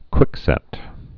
(kwĭksĕt)